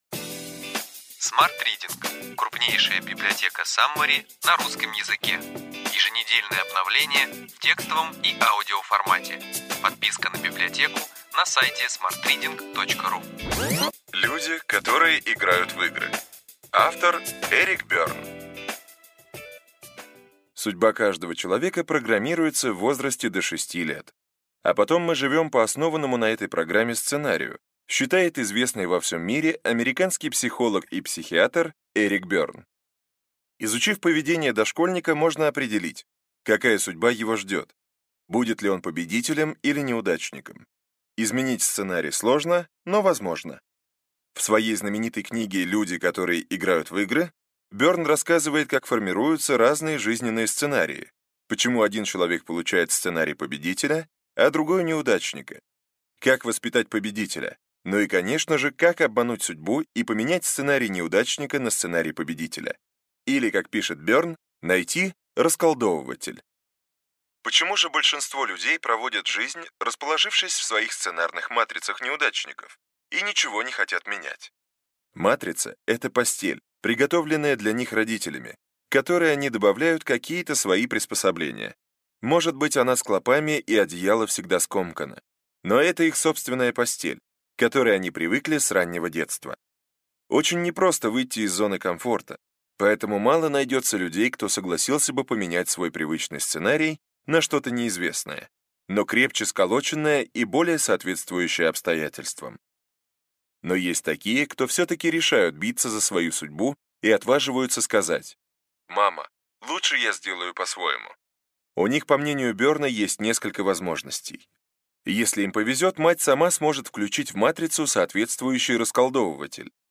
Аудиокнига Ключевые идеи книги: Люди, которые играют в игры.